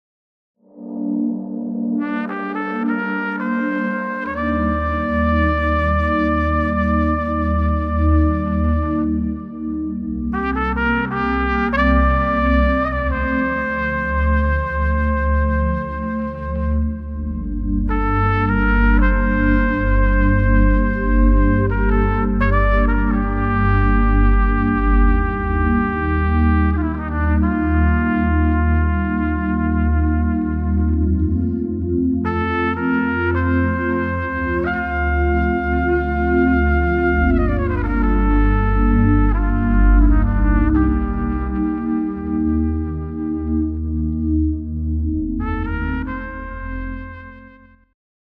Trumpet